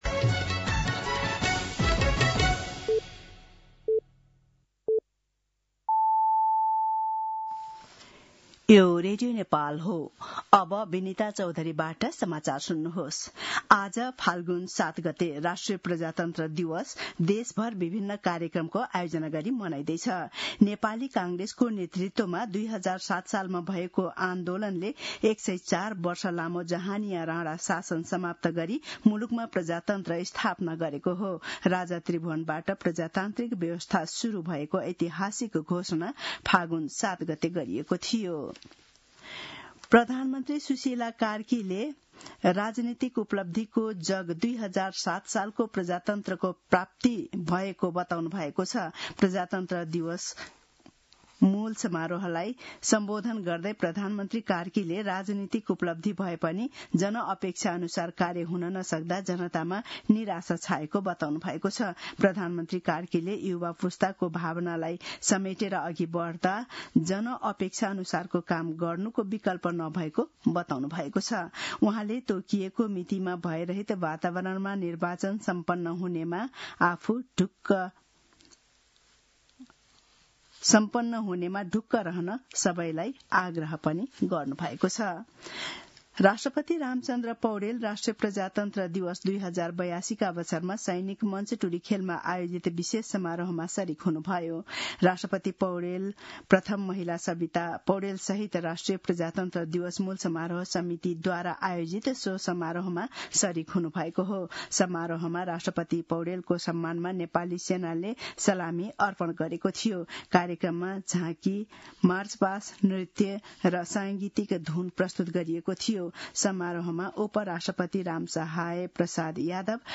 मध्यान्ह १२ बजेको नेपाली समाचार : ७ फागुन , २०८२